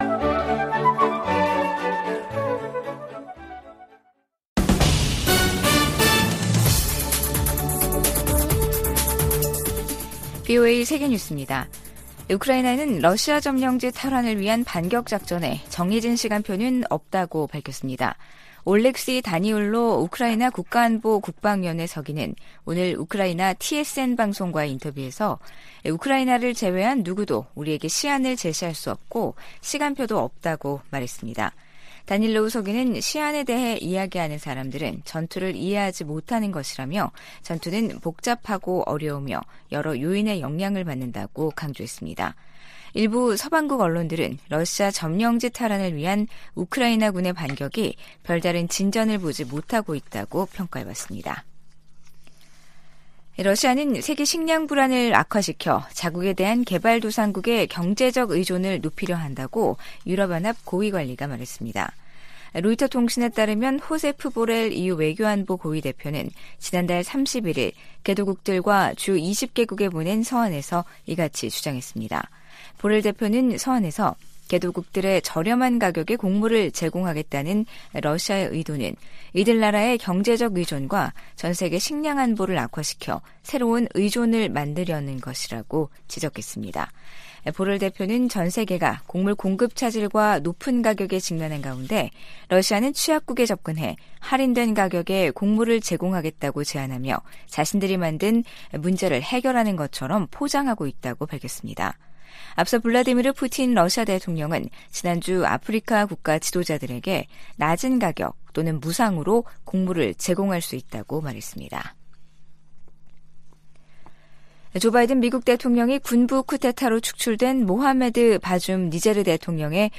VOA 한국어 간판 뉴스 프로그램 '뉴스 투데이', 2023년 8월 3일 3부 방송입니다. 북한이 무단 월북 미군 병사 사건과 관련해 유엔군사령부에 전화를 걸어왔지만 실질적인 진전은 아니라고 국무부가 밝혔습니다. 핵확산금지조약(NPT) 당사국들이 유일하게 일방적으로 조약을 탈퇴한 북한을 비판했습니다. 김영호 한국 통일부 장관이 현 정부에서 종전선언을 추진하지 않을 것이라고 밝혔습니다.